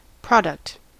Ääntäminen
Synonyymit wares goods production output creation merchandise Ääntäminen US UK : IPA : /ˈpɹɒd.ʌkt/ US : IPA : /ˈpɹɑd.ʌkt/ IPA : /ˈpɹɑd.əkt/ Lyhenteet (laki) Prod.